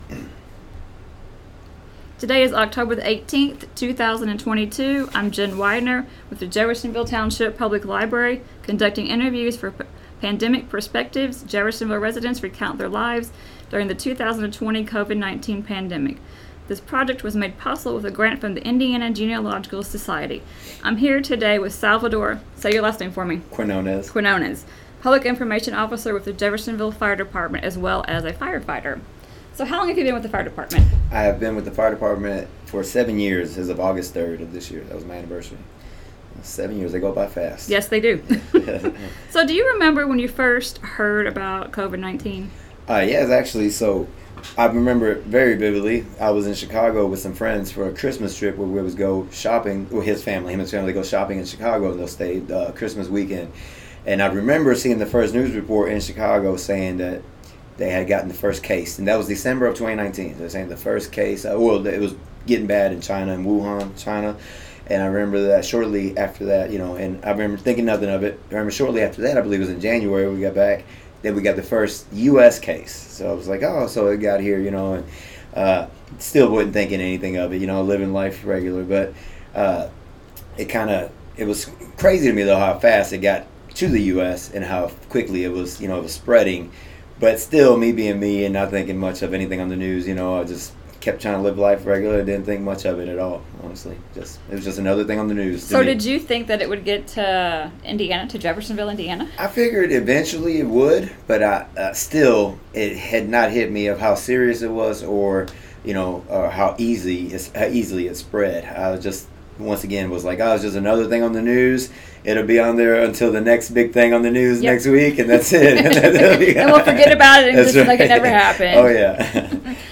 Oral Histories As the Covid-19 pandemic progressed and continued the need to capture people's stories grew.